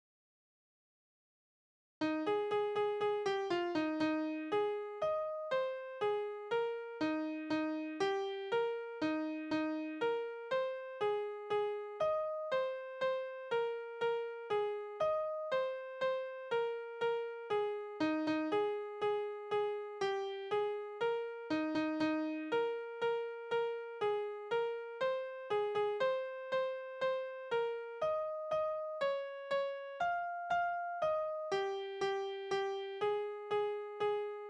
Dialoglieder: Der Grobschmied und sein studierter Sohn
Tonart: As-Dur
Taktart: 8/4
Tonumfang: große None
Besetzung: vokal